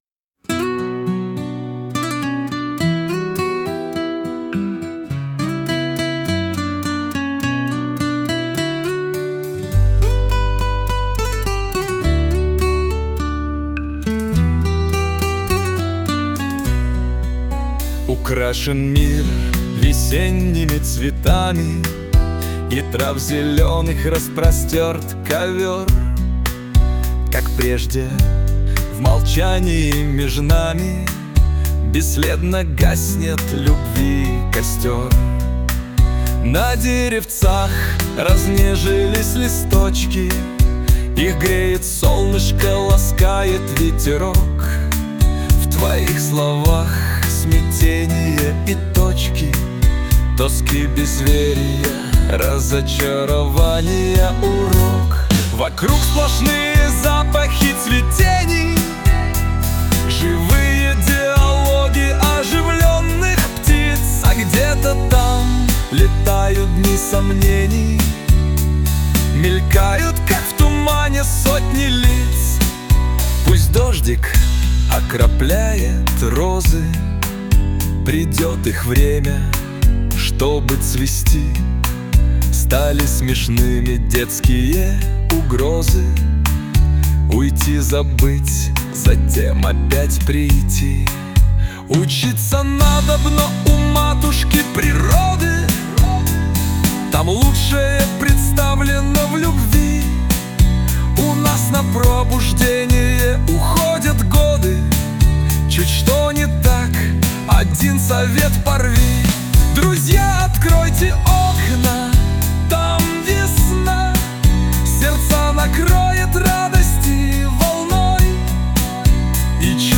ТИП: Пісня
СТИЛЬОВІ ЖАНРИ: Романтичний